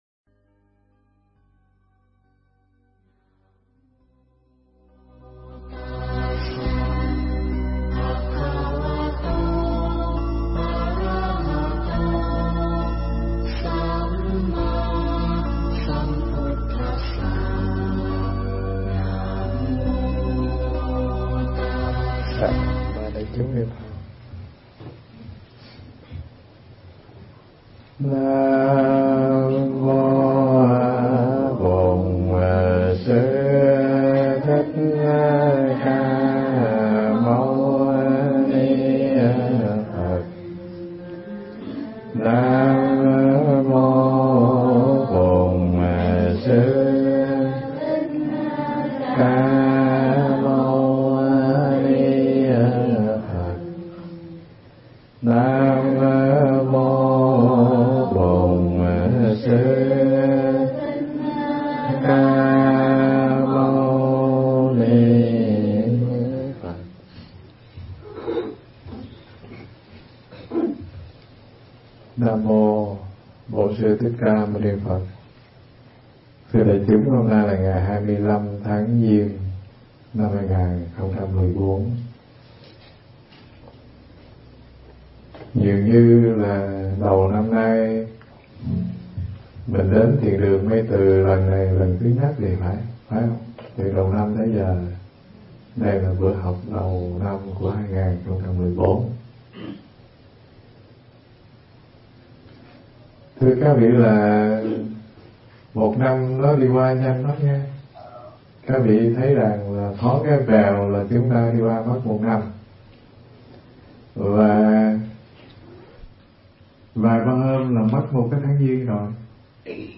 Nghe Mp3 thuyết pháp Mây Từ
Mp3 pháp thoại Mây Từ